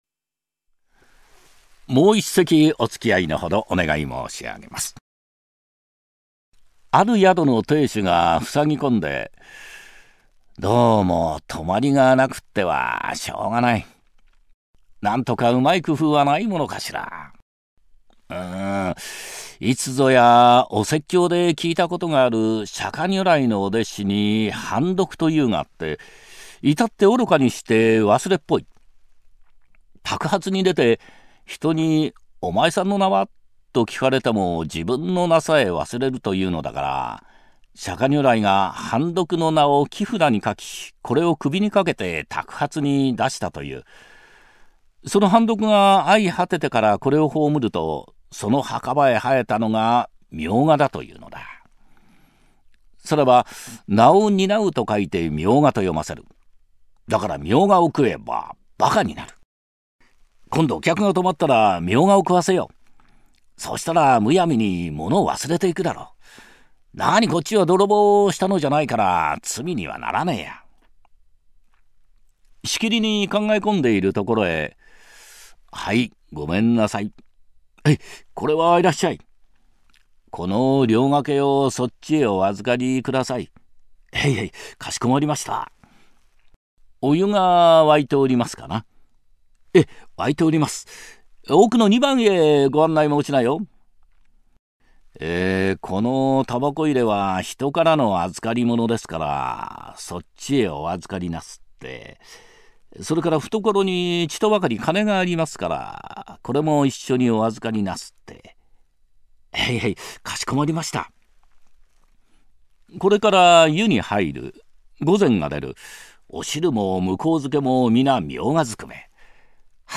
朗 読